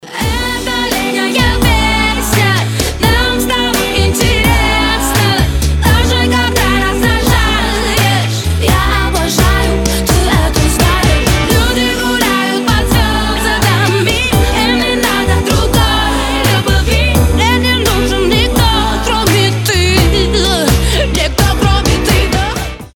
• Качество: 320, Stereo
женский вокал
RnB
романтические